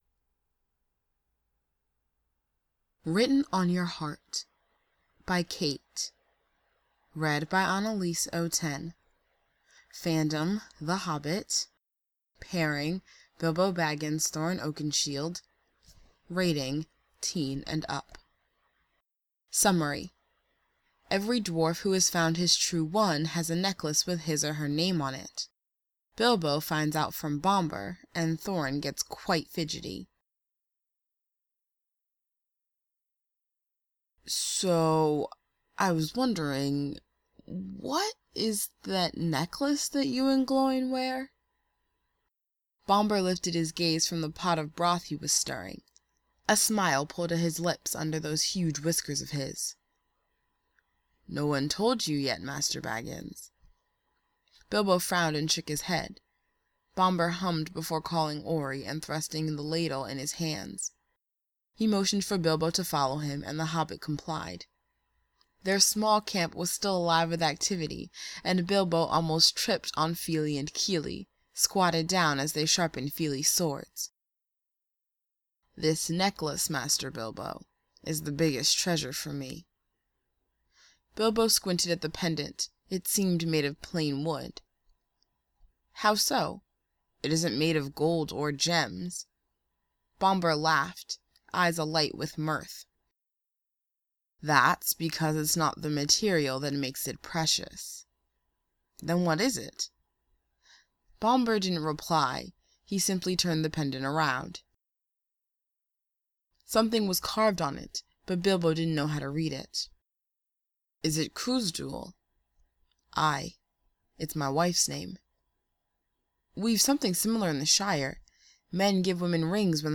audiofic archive